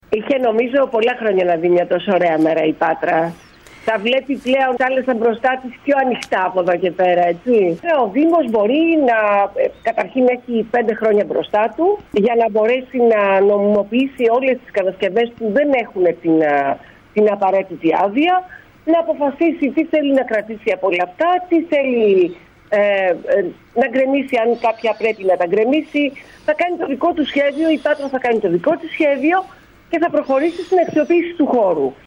Ικανοποιημένη  δηλώνει σήμερα και στην ΕΡΤ ΠΑΤΡΑΣ στην εκπομπή «Στον αέρα… μέρα παρά μέρα».